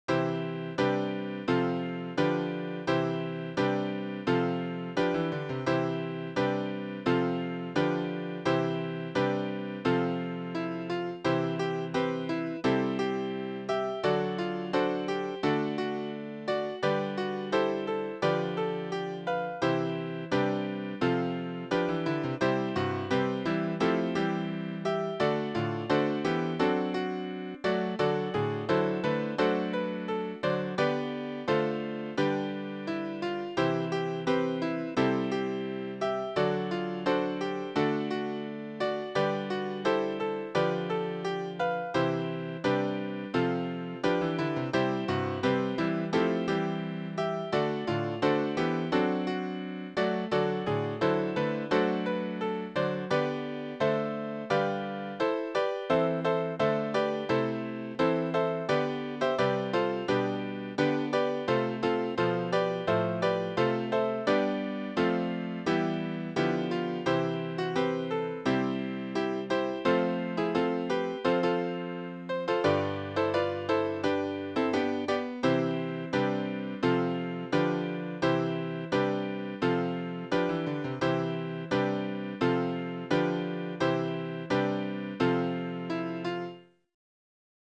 Voicing/Instrumentation: SATB , Piano Solo